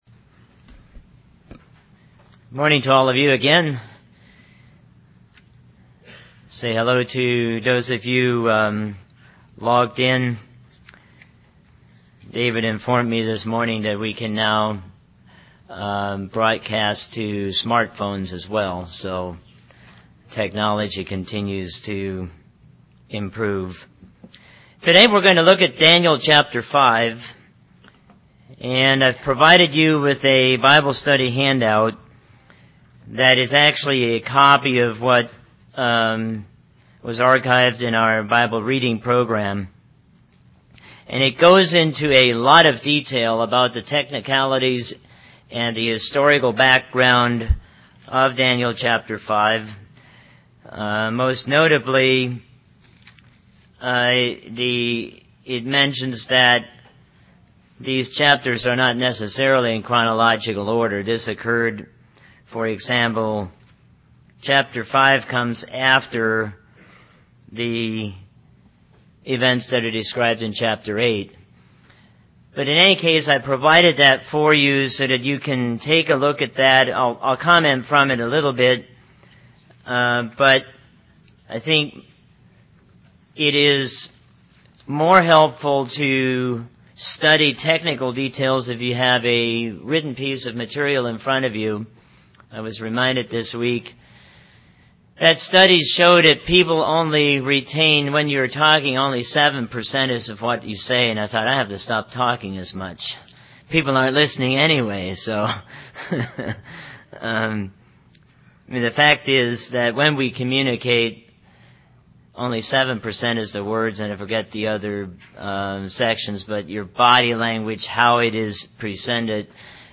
A study of the book of Daniels continues with chapter 5. Bible Study 18 Page Handout